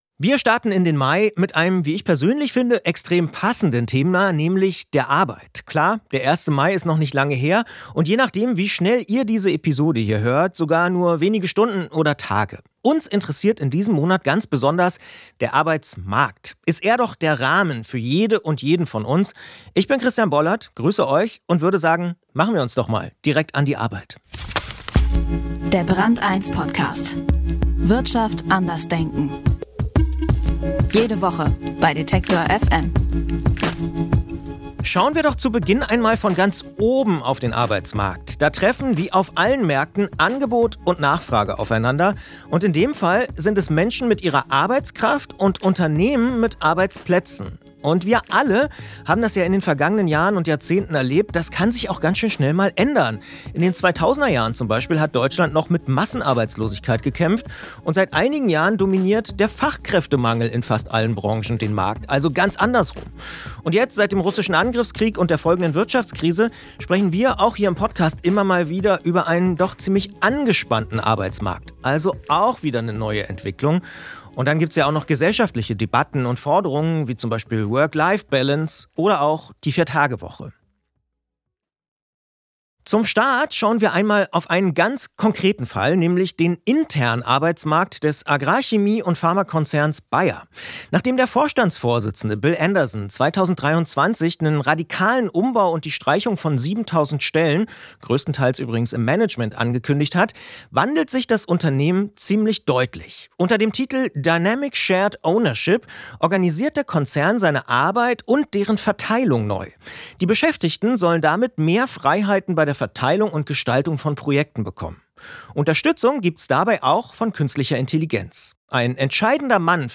Wirtschaft brand eins Podcast